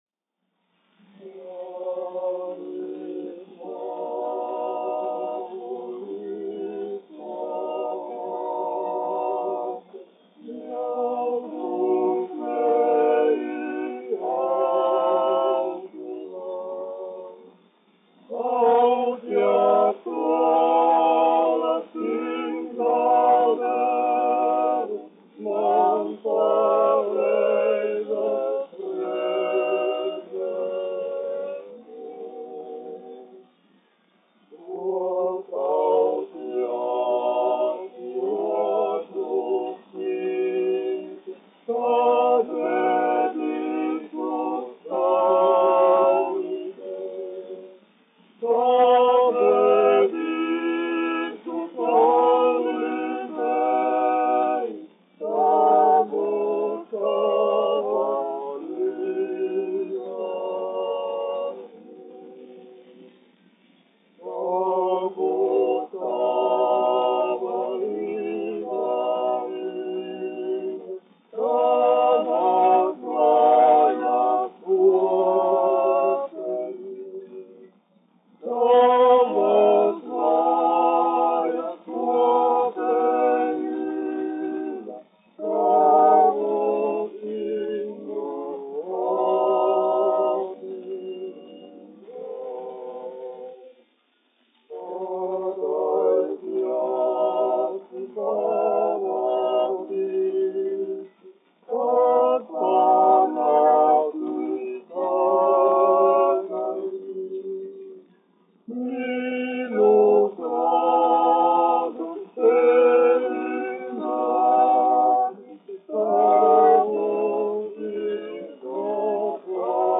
1 skpl. : analogs, 78 apgr/min, mono ; 25 cm
Vokālie kvarteti
Latviešu tautasdziesmas
Skaņuplate
Latvijas vēsturiskie šellaka skaņuplašu ieraksti (Kolekcija)